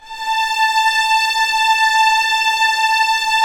Index of /90_sSampleCDs/Roland LCDP13 String Sections/STR_Violins IV/STR_Vls7 f slo